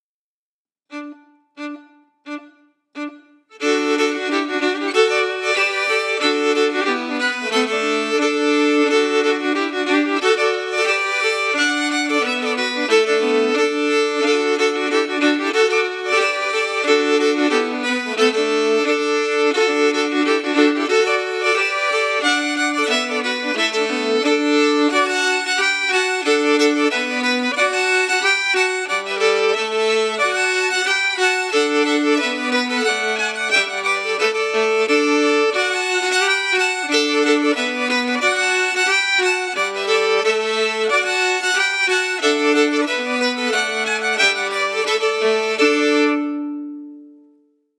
Key: D
Form: Reel
MP3: (Melody emphasis, played by the composer)
Genre/Style: “Pseudo-Appalachian”, according to the composer